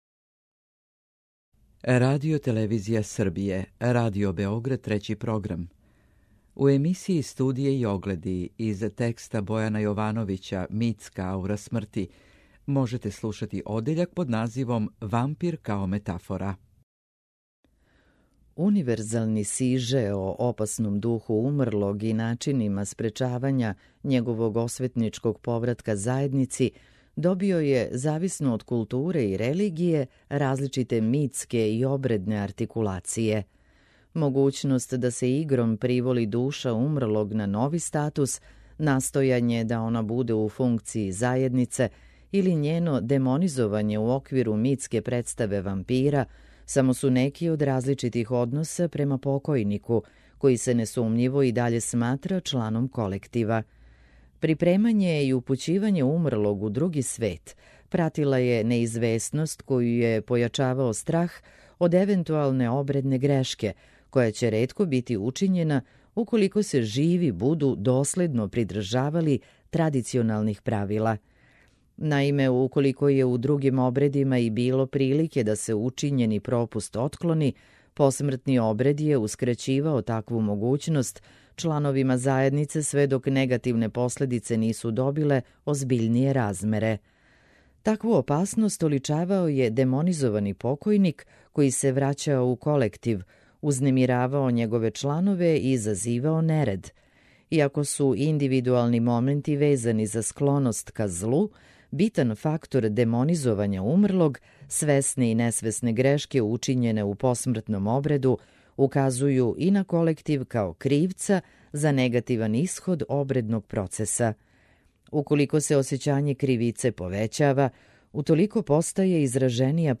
У првој емисији овог циклуса прочитаћемо одељак „Вампир као метафора” из овог Јовановићевог текста.